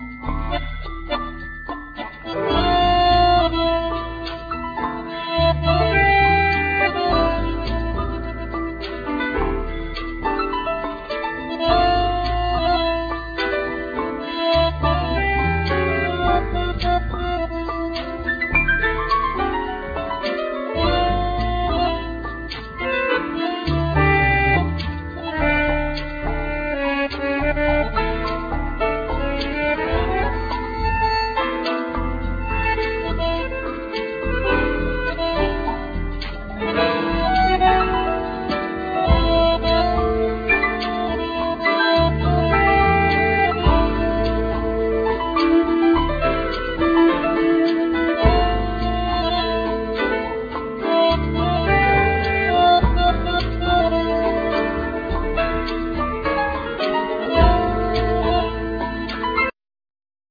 Bandneon,Glockenspiel
Piano,Synthsizer
Violin
Tenor saxophone
Drams,Tabla